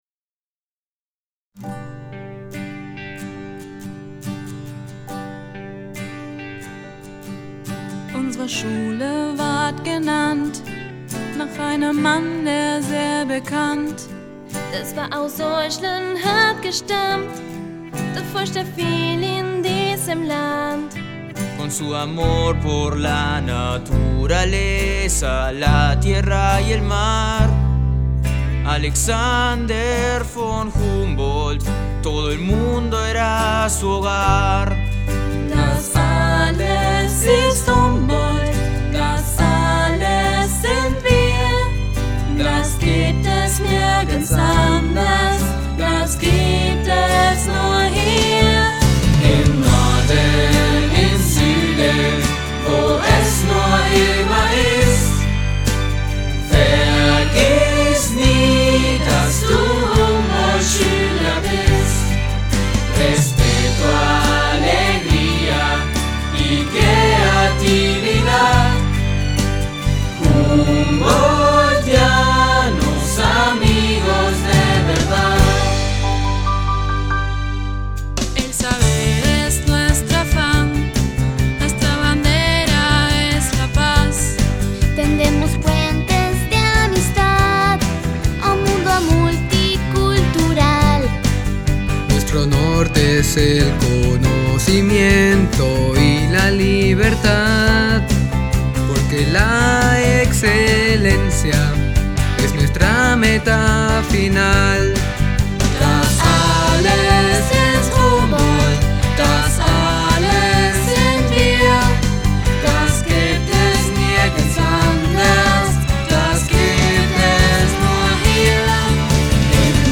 Himno del colegio Descargar en MP3 Descargar en PDF